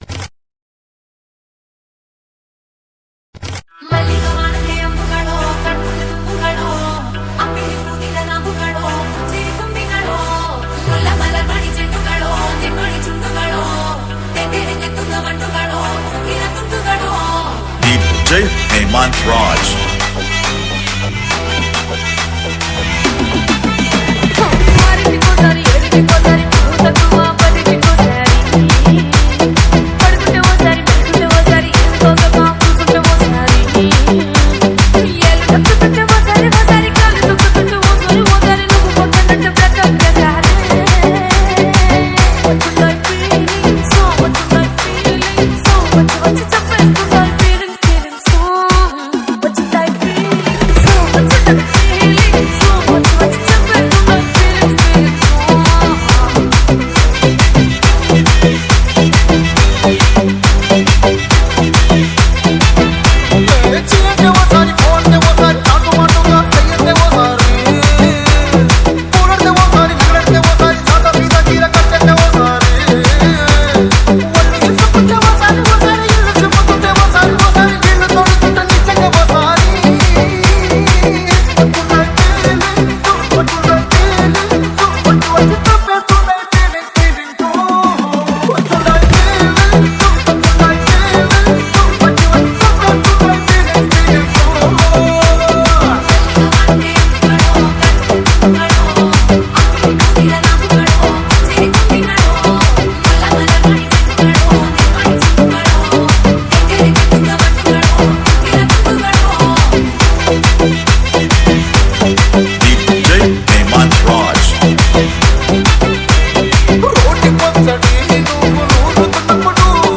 TAMIL ITEM DJ REMIX SONG